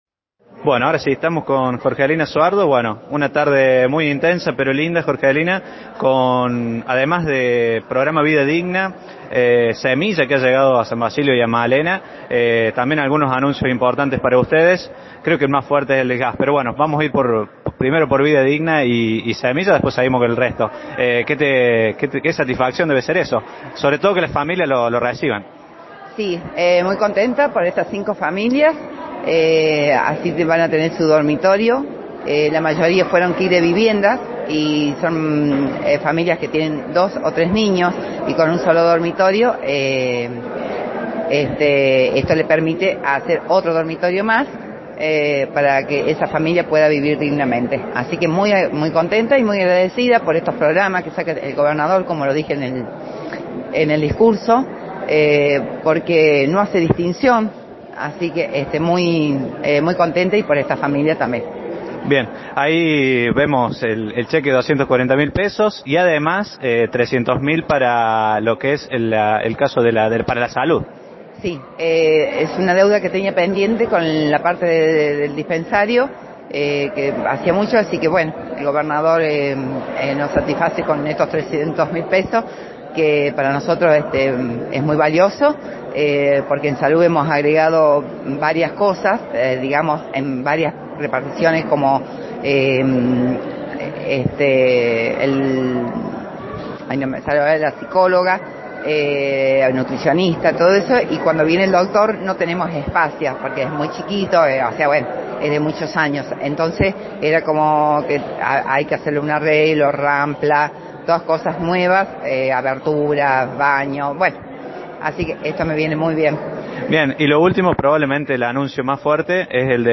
En el Salón Comunal se pudieron escuchar los discursos de la Presidente Comunal Jorgelina Soardo y el mismo Gobernador.
Compartimos audio con la palabra de Jorgelina Soardo: